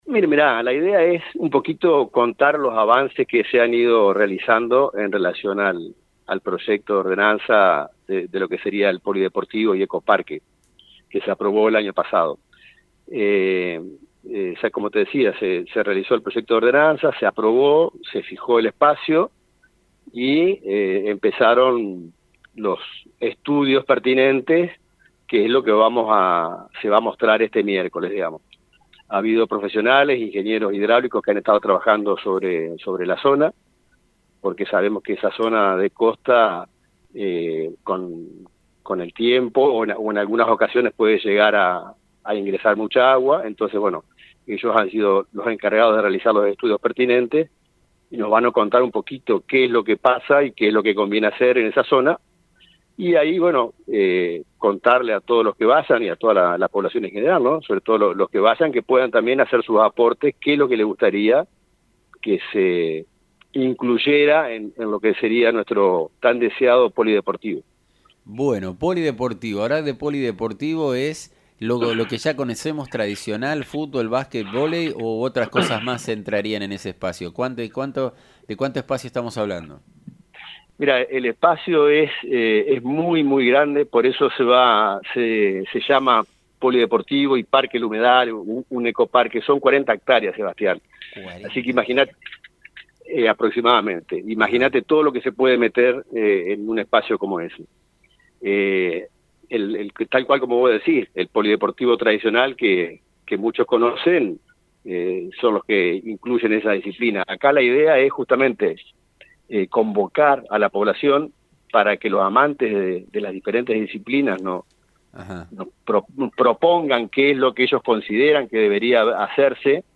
Leonel Anderson – concejal